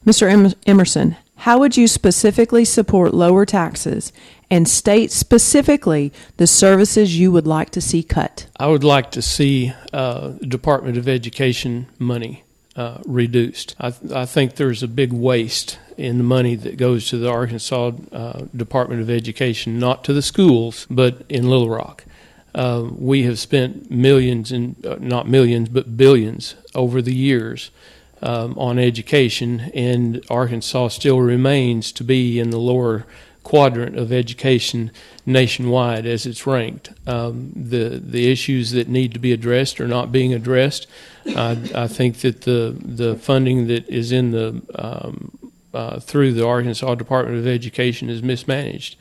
District 4 Representatives met Thursday for another forum on KTLO-FM